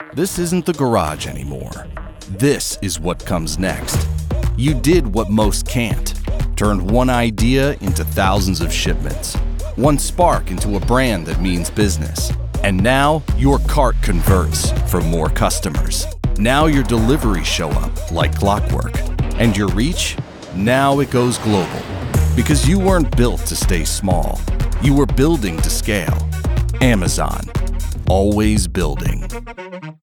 Bold · Confident · Edgy
A modern, assertive read for a tech-forward DTC brand. Punchy delivery with conversational energy.